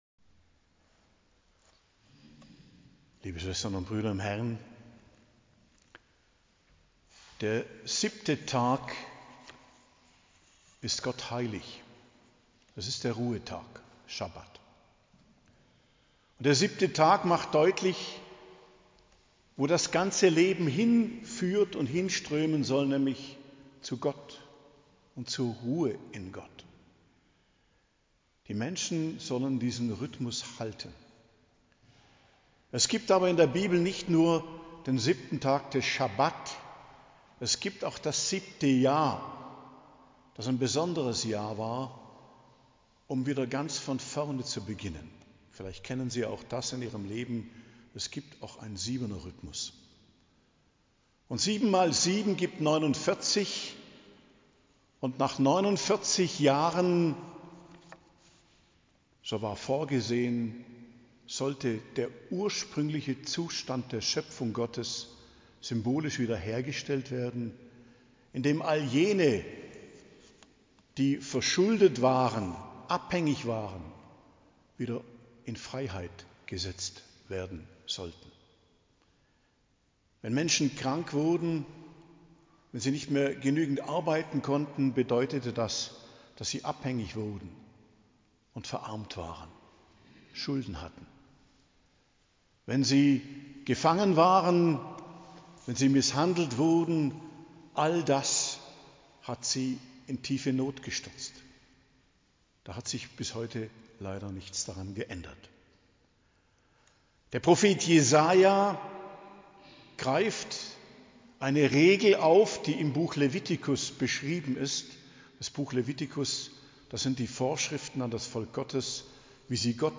Predigt zum 3. Sonntag i.J. am 26.01.2025 ~ Geistliches Zentrum Kloster Heiligkreuztal Podcast